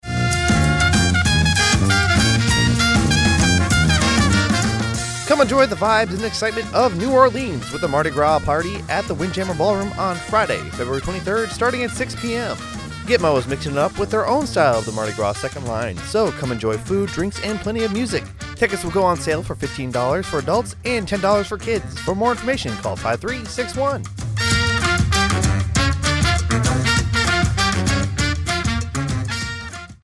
A radio spot informing listeners of the Mardis Gras event hosted by MWR on Naval Station Guantanamo Bay, Cuba.